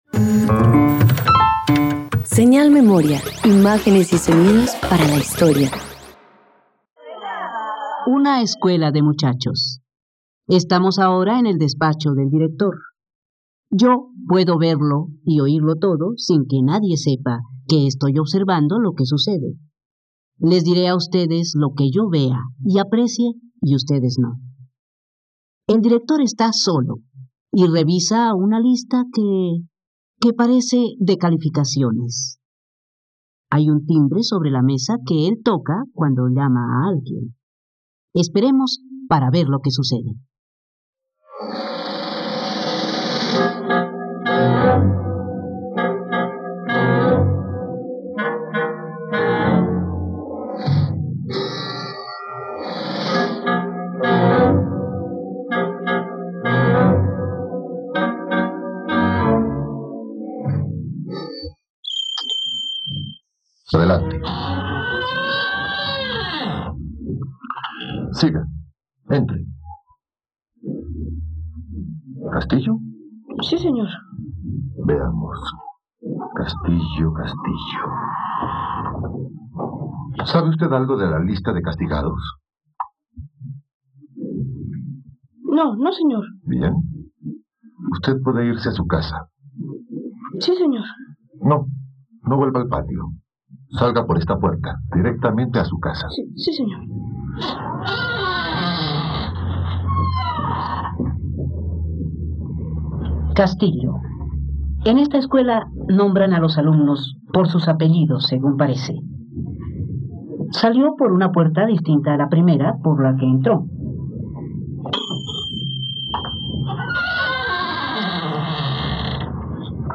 ..Radioteatro. Escucha la adaptación radiofónica de “Los delatores” de Álvaro Yuque.